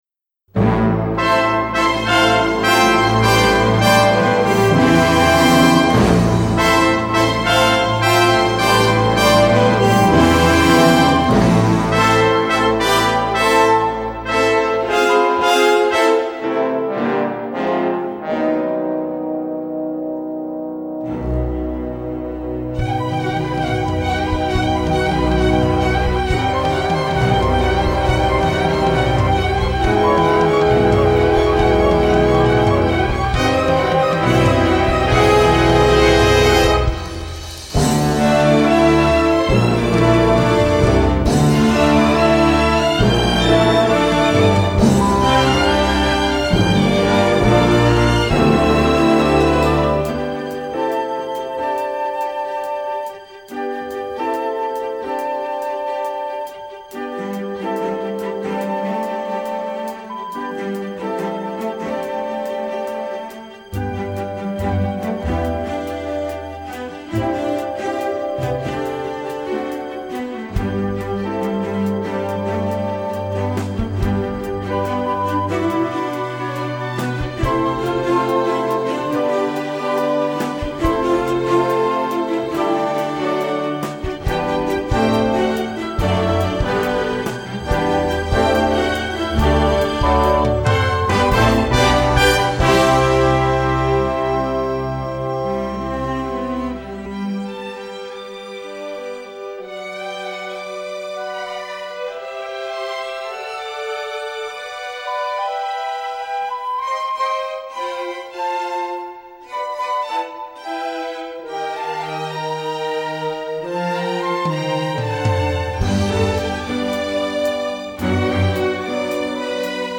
Category: Full Orchestra